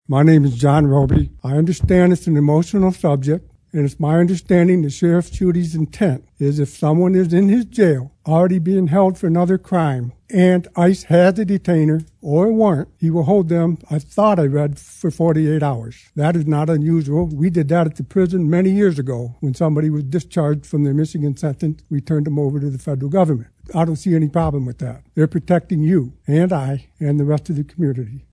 Jackson, Mich. (WKHM) — The Jackson County Board of Commissioners meeting on Tuesday night featured a packed room and multiple hours of public comment regarding the Sheriff’s Office’s (JCSO) 287(g) Agreement with ICE.
While the majority of speakers at the meeting were against the agreement, public comment also featured multiple citizens in support of the JCSO and their partnership with ICE, including a former MDOC employee.